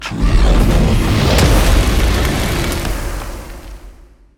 spell-impact-2.ogg